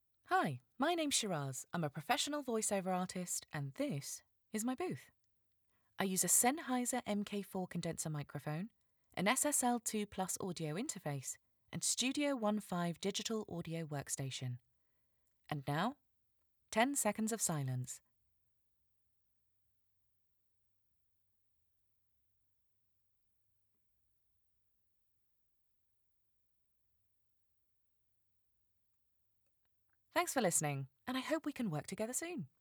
Raw Studio Test Request a free demo of your script!